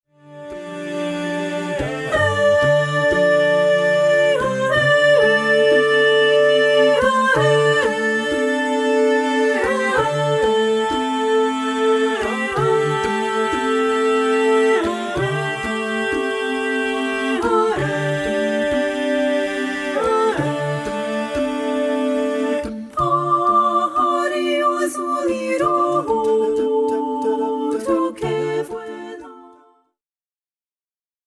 • Full Mix Track